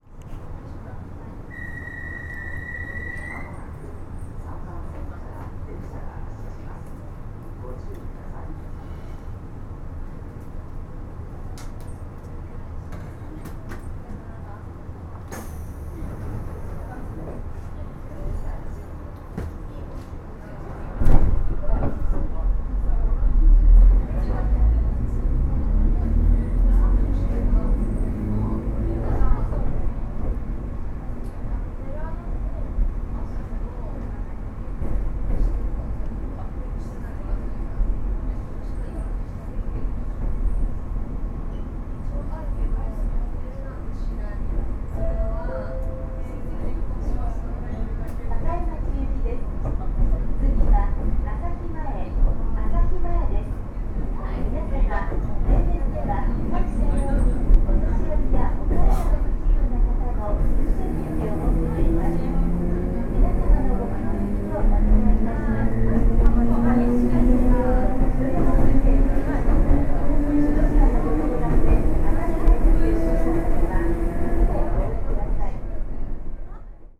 一方、瀬戸線の現行車両型式のひとつ「6750系」は、「釣り掛け式」と呼ばれる旧来の駆動方式の台車を搭載しており、性能面では不利ながら、独特の大きな走行音を発するために鉄道ファンから根強い人気を誇っている。
6750系の走行音(尾張旭駅始発列車)・MP3形式……モーターがオンになる0分22秒と1分00秒のあたりで独特の走行音が聞こえる